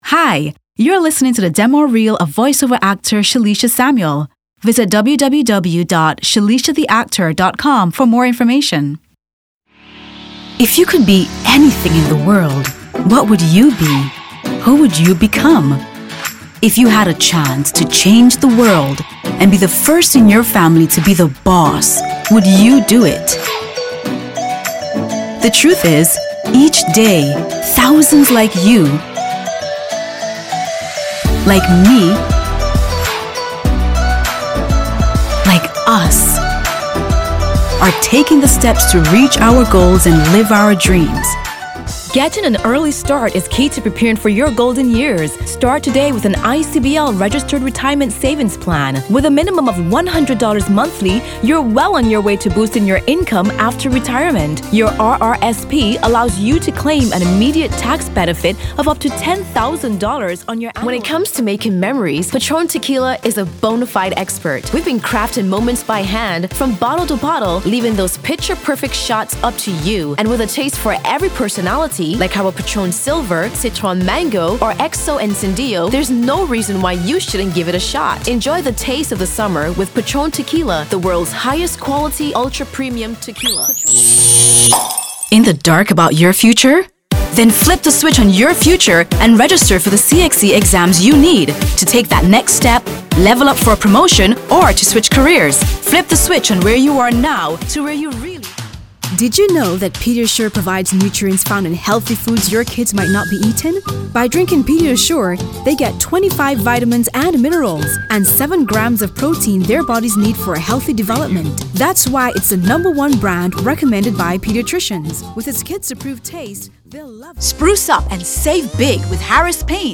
Behind every memorable ad is a memorable voice
Commercial Voice Overs